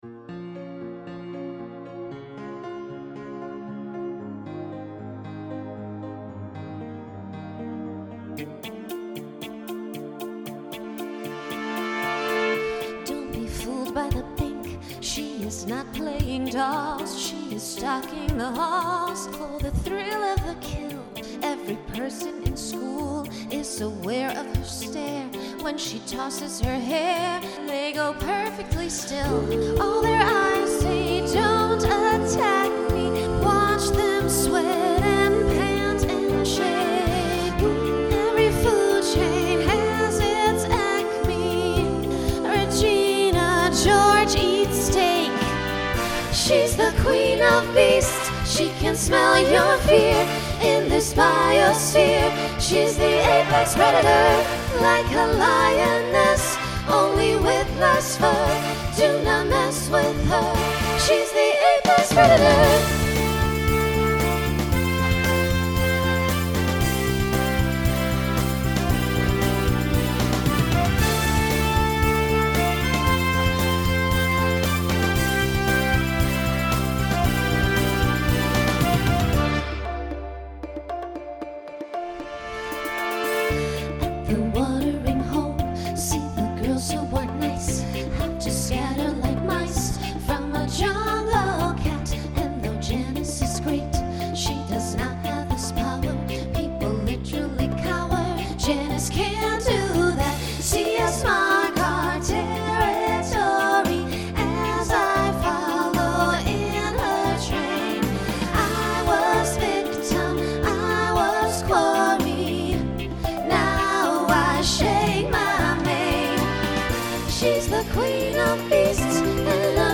Voicing SSA Instrumental combo Genre Broadway/Film